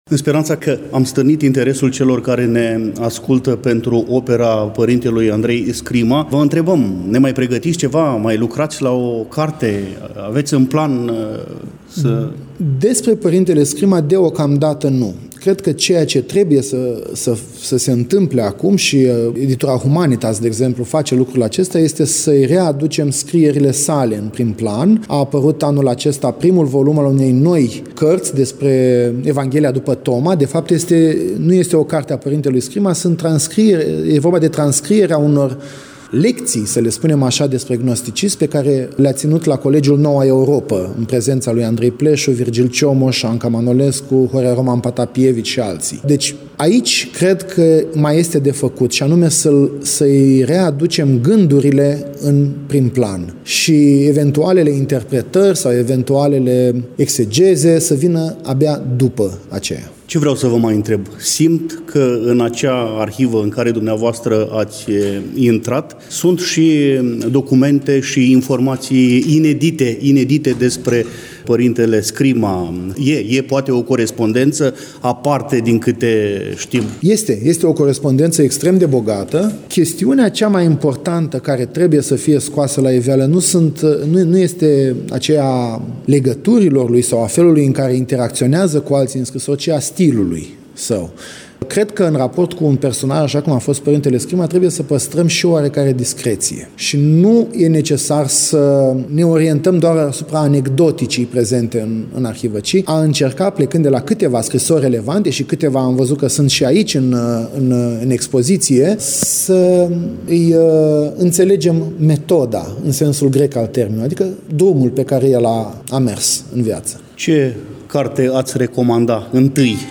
Înaintea conferinței l-am invitat la dialog pe domnul profesor.